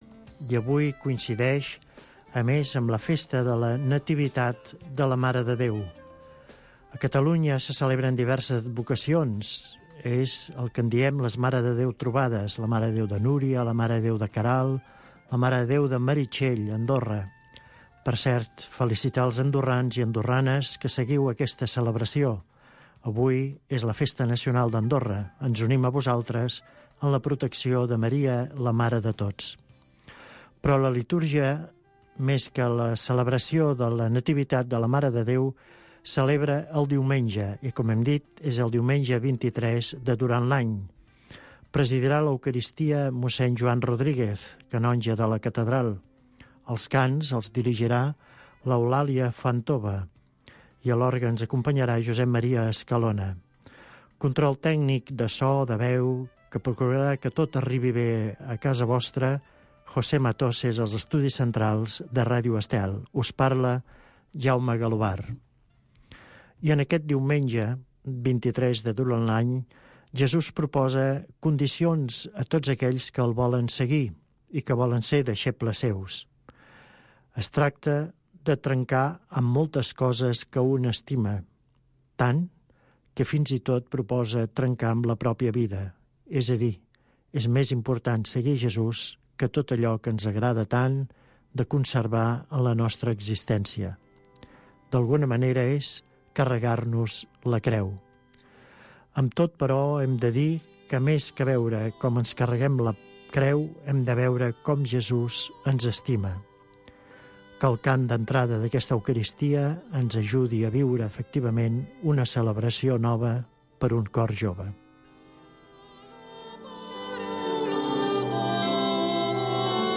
s de la catedral de Barcelona es retransmet tots els diumenges i festius la missa, precedida d’un petit espai d’entrevista